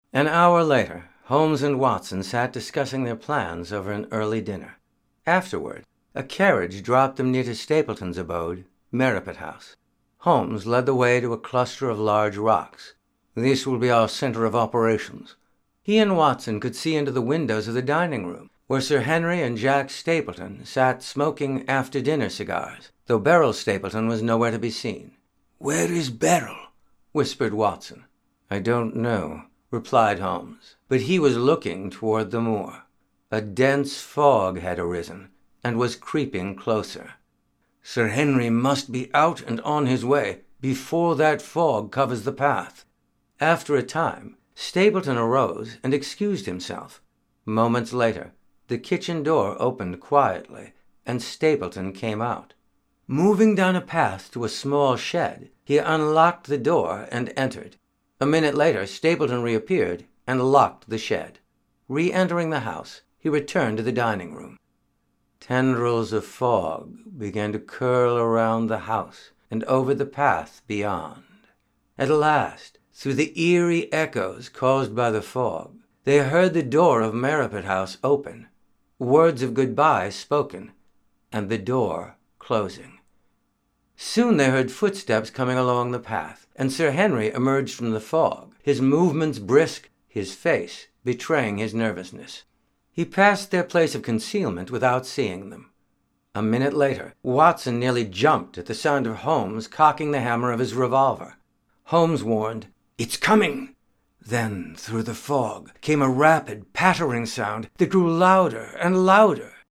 Back to Storytelling Recordings ...